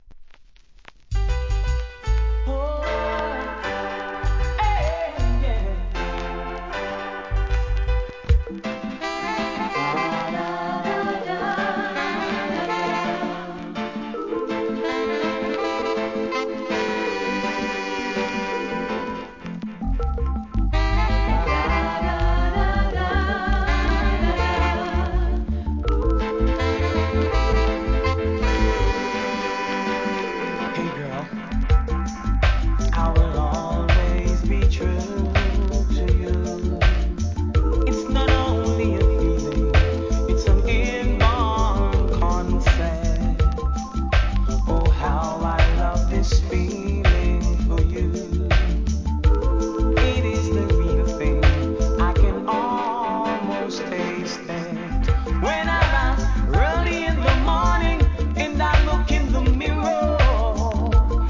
REGGAE
ROCKSTEADYのリメイクRHYTHM